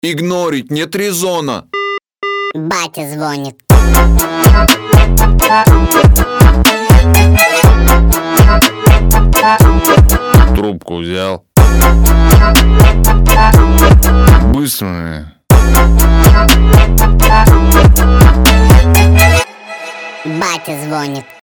Басы